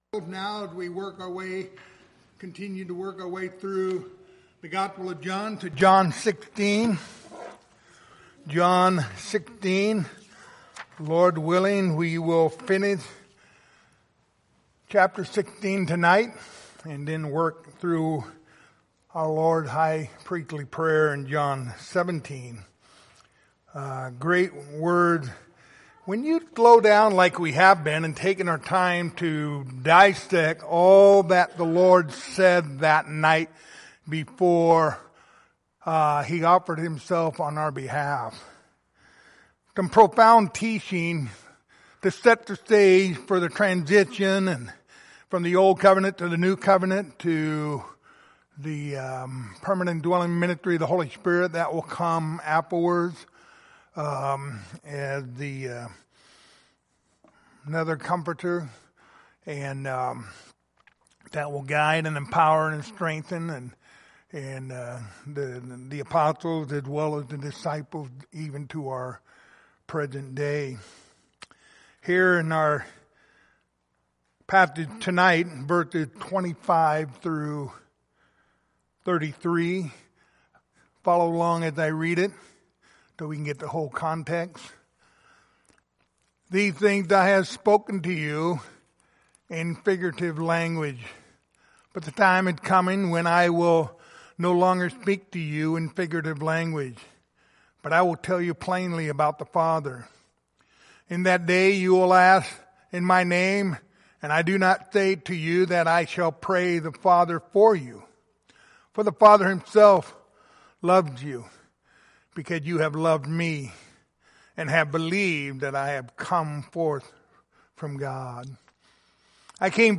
Passage: John 16:25-33 Service Type: Wednesday Evening